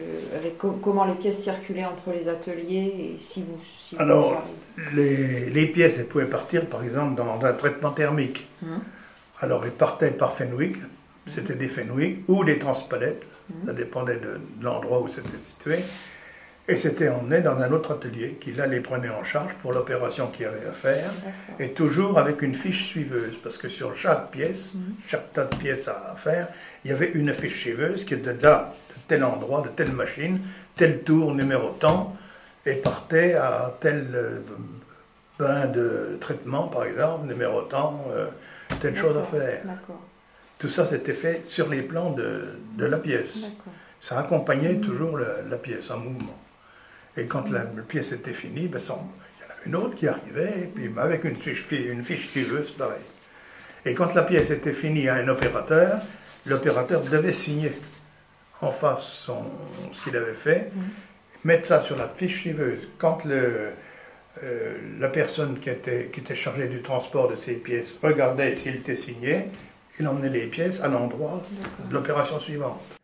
Cet article s’appuie sur une collecte audiovisuelle de la mémoire de l’industrie de la ville de Vierzon donnant lieu à la création d’un site Internet.